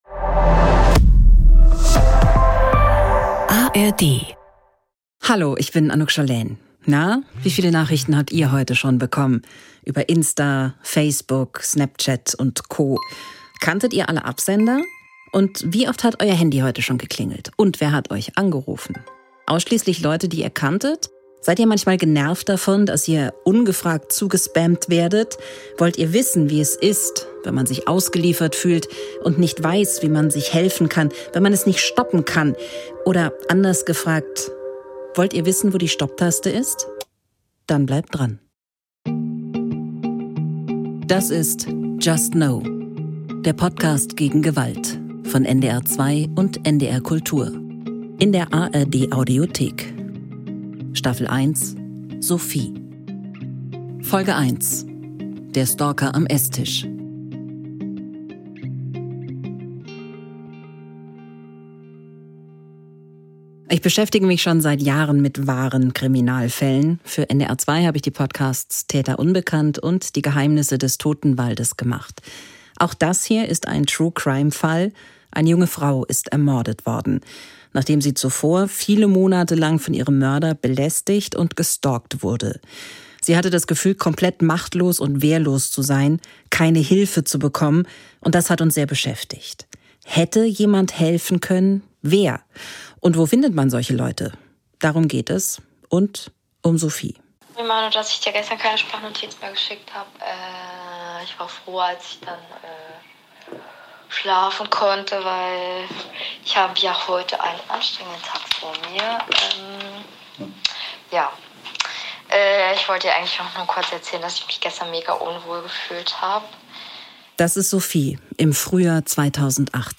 just no! ist ein True Crime-Podcast von NDR 2 und NDR Kultur von 2023.